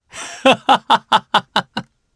Roman-Vox_Happy3_jp_b.wav